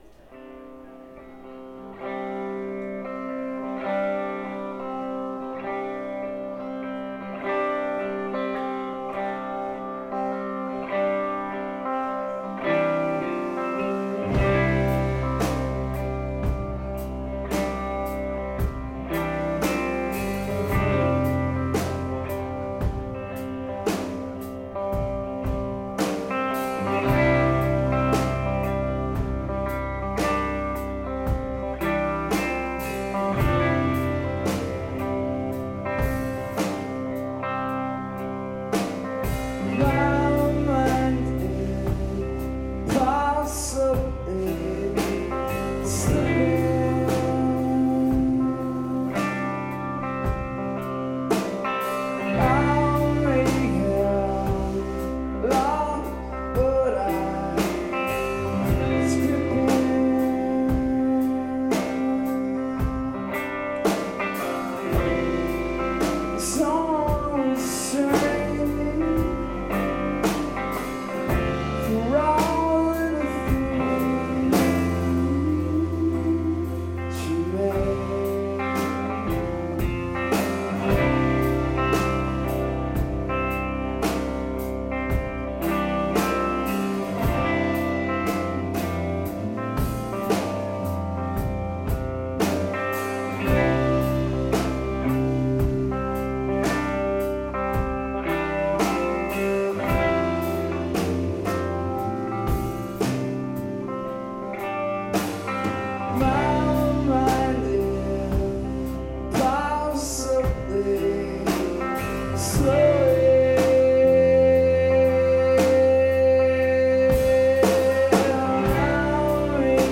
northstar philadelphia July 18 2001